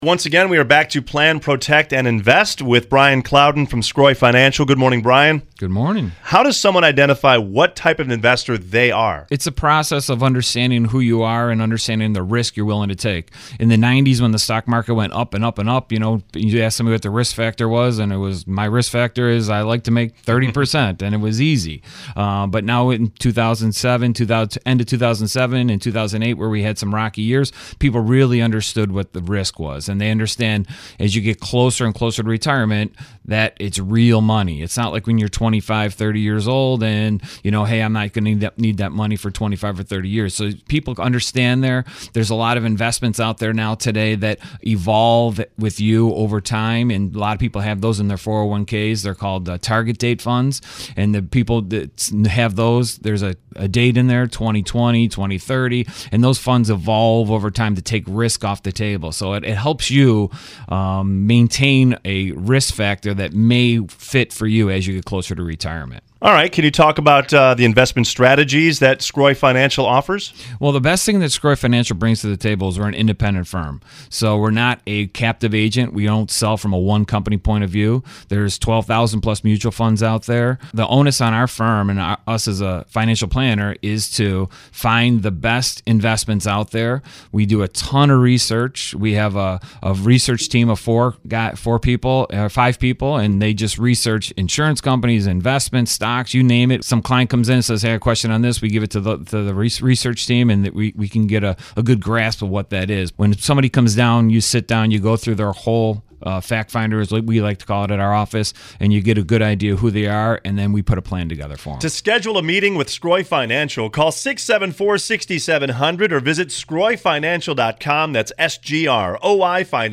interview
Plan.Protect.Invest. Episode #29 WEEKLY SEGMENT ON WYRK You can catch our weekly Plan.Protect.Invest. segment live on WYRK 106.5FM at 7:20am every Wednesday.